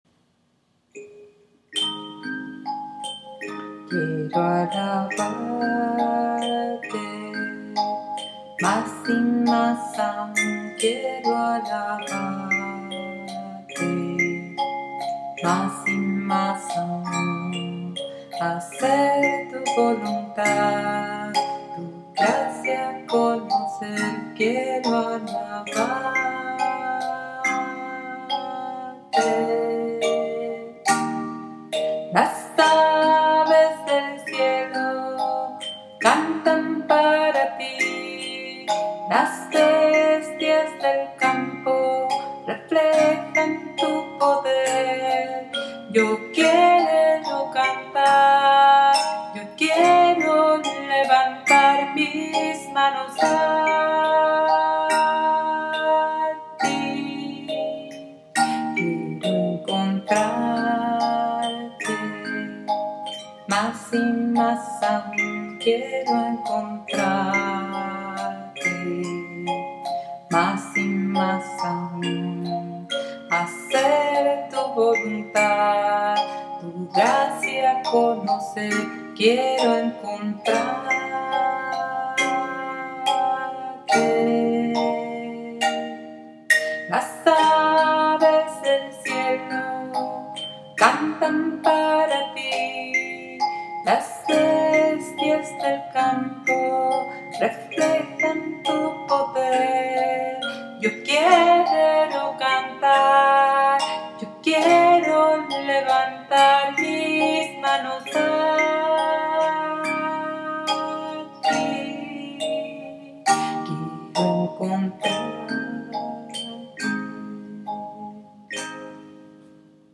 CANCION